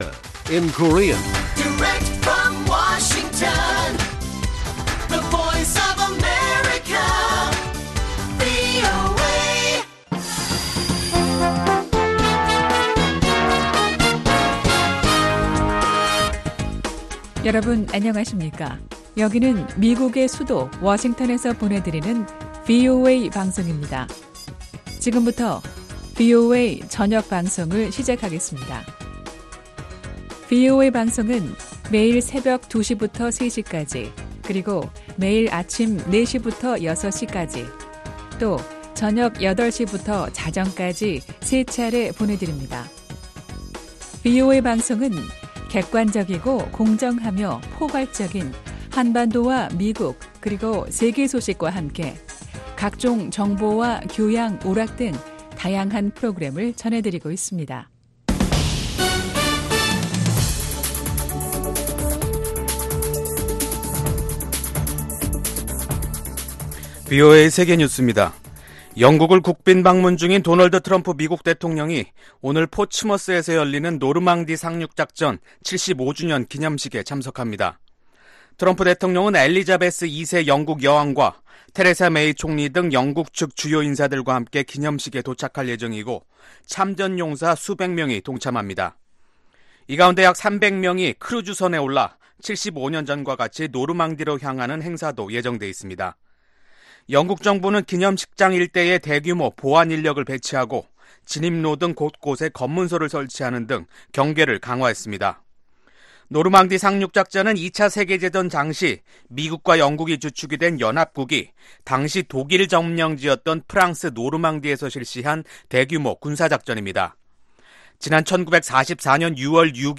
VOA 한국어 간판 뉴스 프로그램 '뉴스 투데이', 2019년 6월 5일 1부 방송입니다. 스웨덴 정부는 대북 인도적 지원이 도움을 가장 필요로 하는 계층에 전달되도록 하기 위해 자체적인 ‘감시 프로젝트’를 이행하고 있다고 밝혔습니다. 북한 경제 전문가들은 북한이 실제 경제 발전을 이루려면 인권증진, 투명한 임금 지불체계, 국제통화기금 가입 등이 필요하다고 지적합니다.